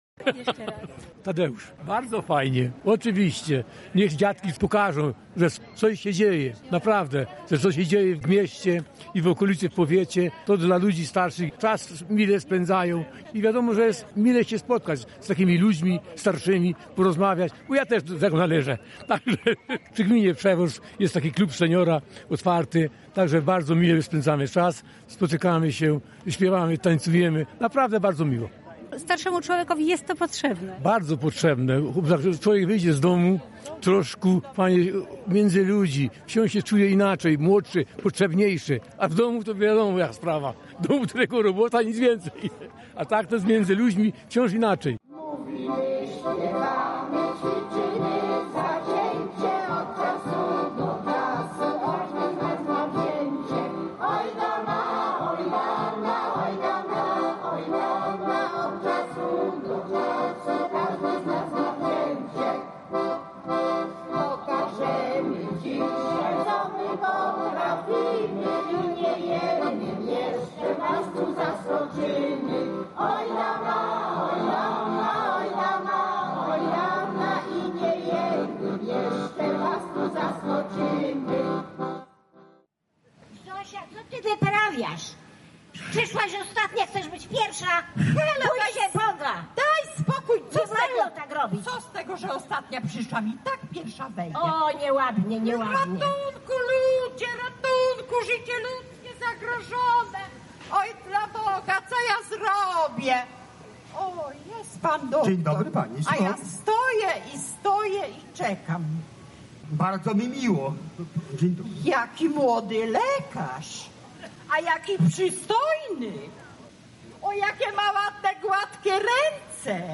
Humory dopisały zarówno widzom jak i aktorom.